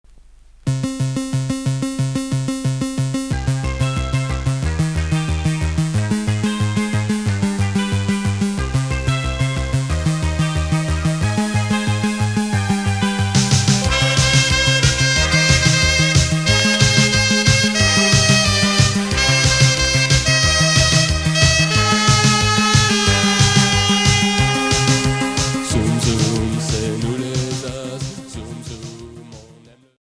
Rock new wave et minimal synth Unique 45t retour à l'accueil